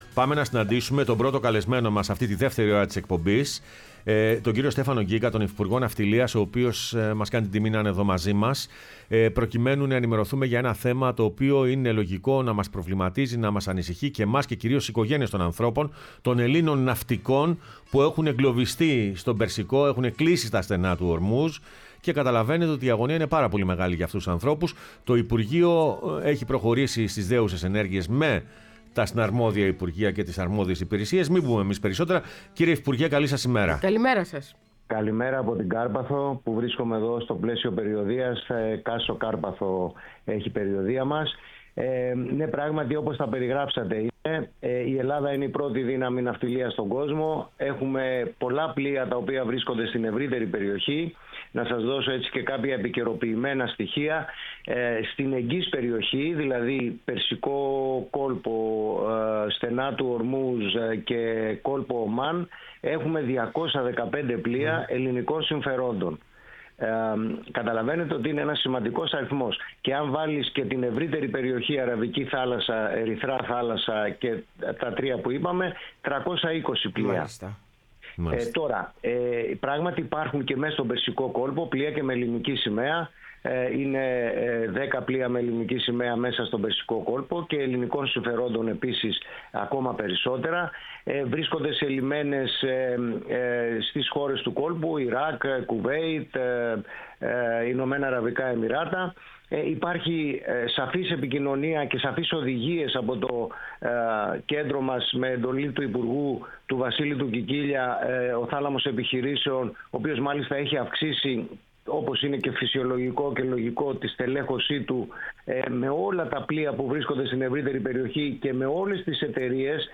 Ο Στέφανος Γκίκας υφυπουργός Ναυτιλίας μίλησε στην εκπομπή Πρωινές Διαδρομές